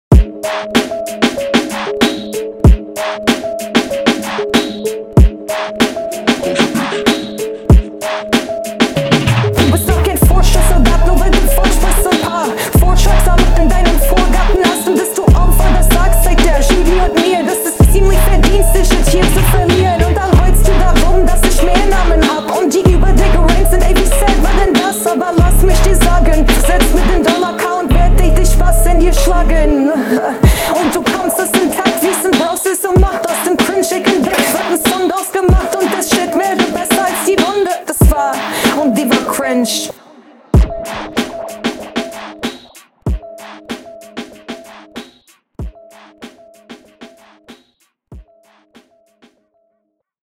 klingt besser, was geht aber auf einmal mit der Stimme ab wtfff